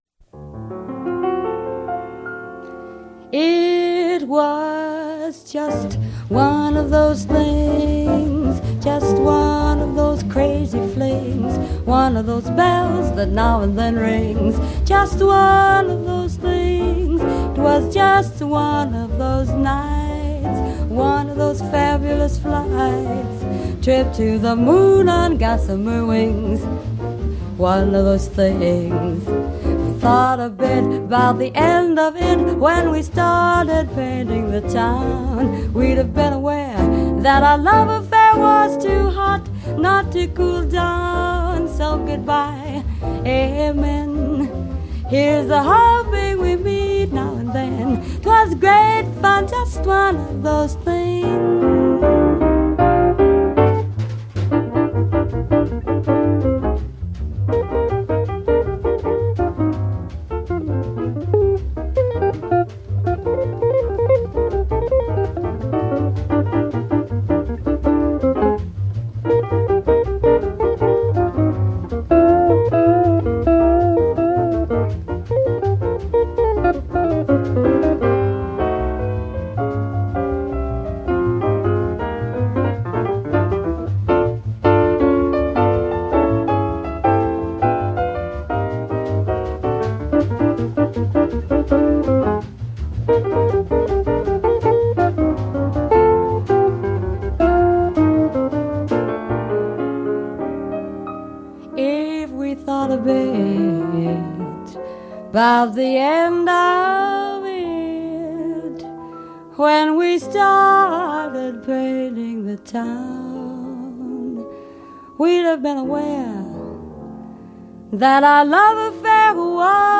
Jazz, Vocal Jazz